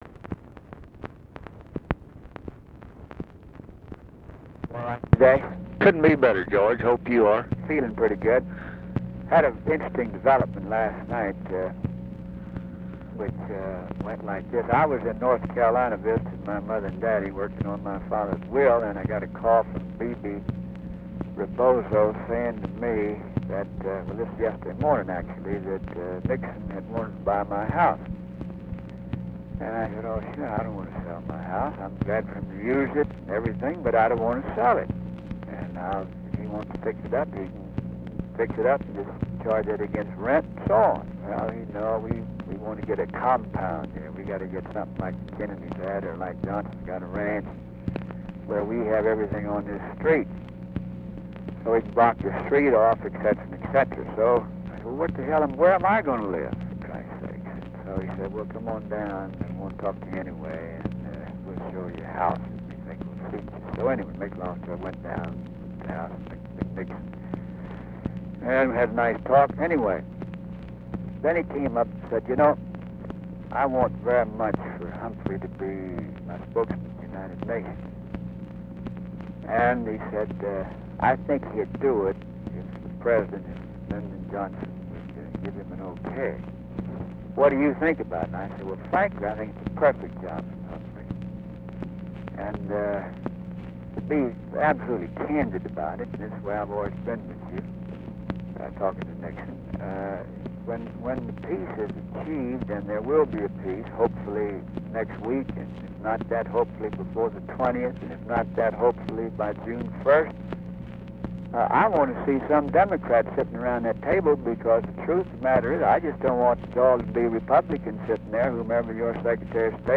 Conversation with GEORGE SMATHERS, November 21, 1968
Secret White House Tapes